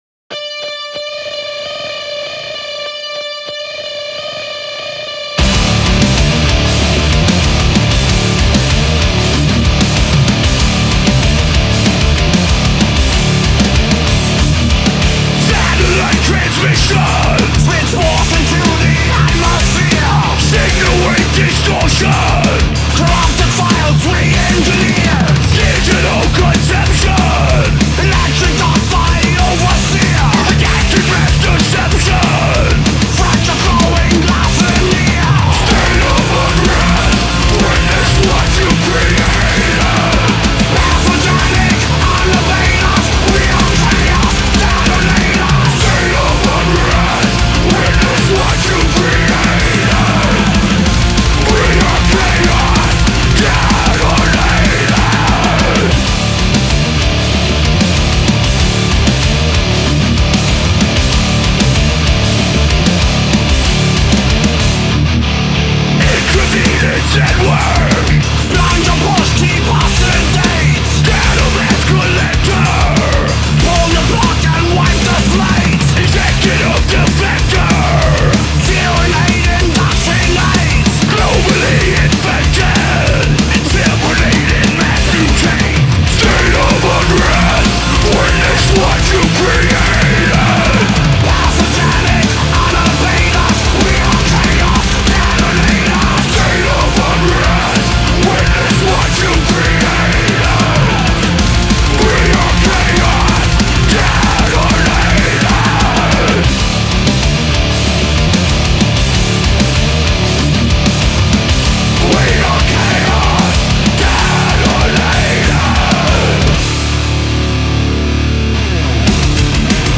هوی متال
گروو متال
ترش متال